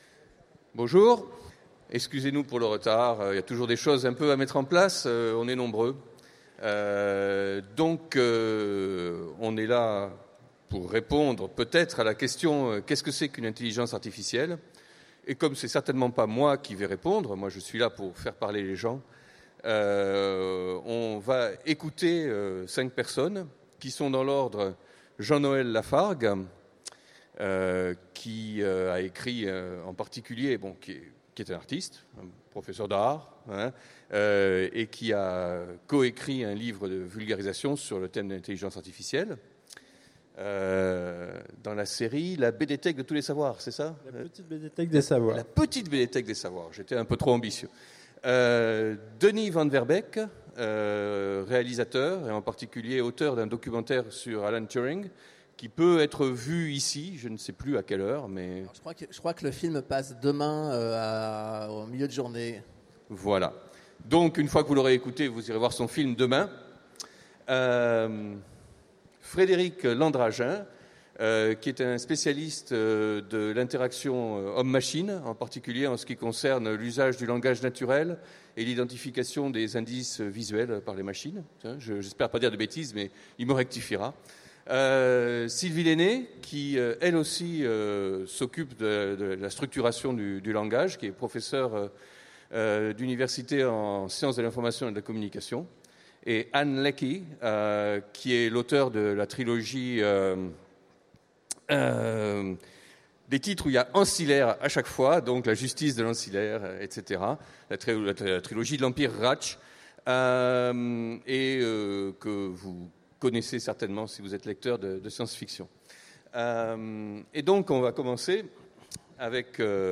Utopiales 2016 : Conférence Qu’est-ce qu’une Intelligence Artificielle ?